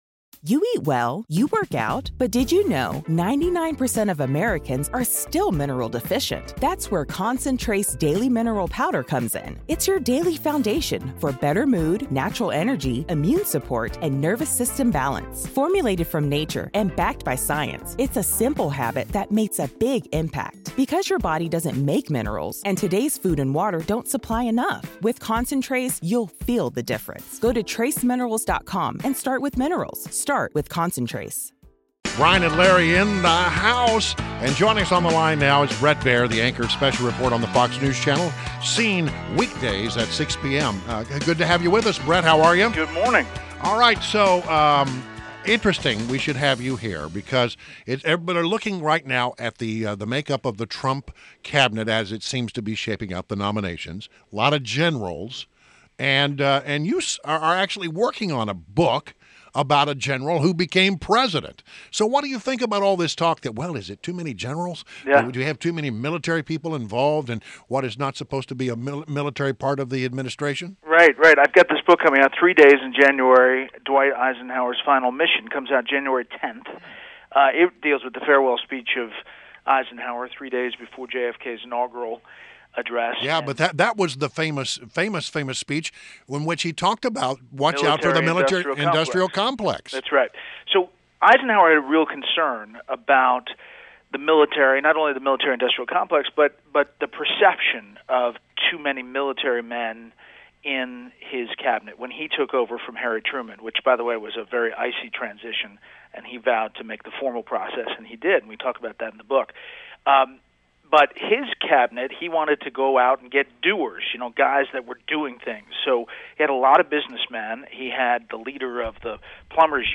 INTERVIEW — BRET BAIER – Anchor of SPECIAL REPORT on FOX NEWS CHANNEL, weekdays at 6 pm – shared his thoughts on Trump’s cabinet and discussed his appearance on Seth Meyers last night.